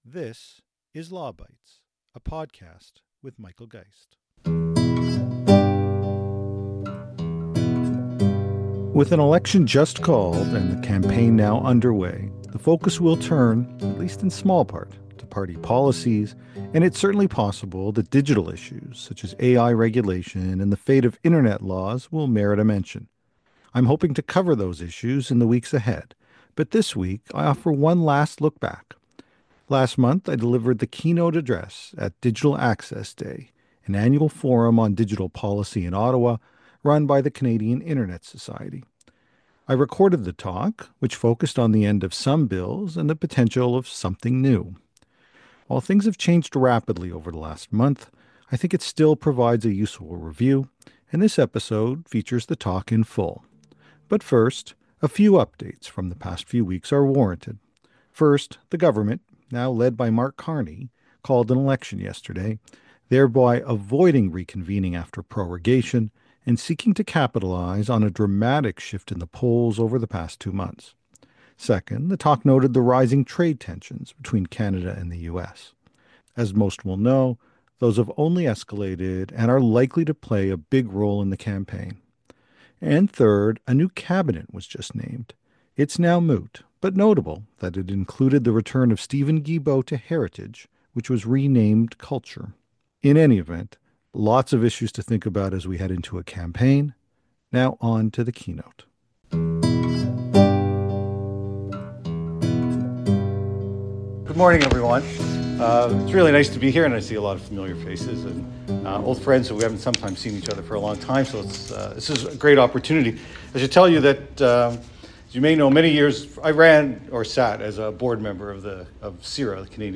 Last month, I delivered the keynote opening address at Digital Access Day, an annual forum on digital policy run by the Canadian Internet Society. I recorded the talk, which focused on the end of some bills and the potential start of something new. While things have changing rapidly over the past month, I think it still provides a useful review and it is included in its entirety in this week’s Law Bytes podcast.